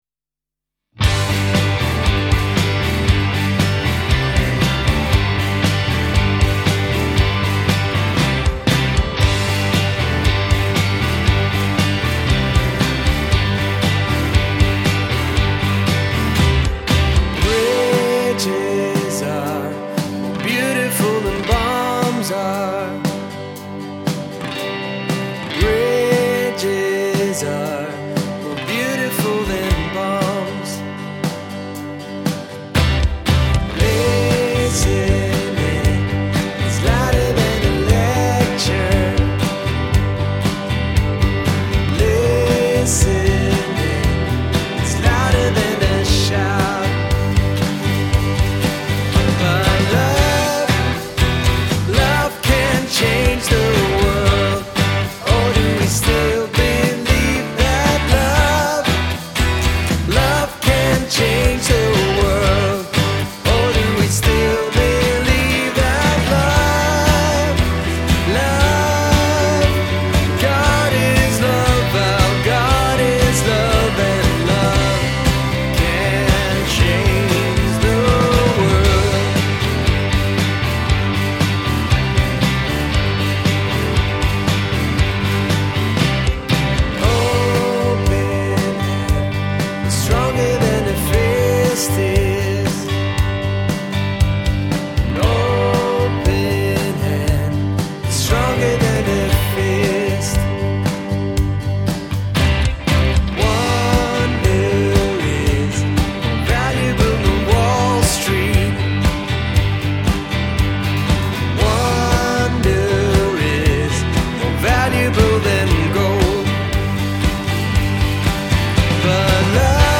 Stellenbosch Gemeente Preke 16 January 2022 || Wysheid ens.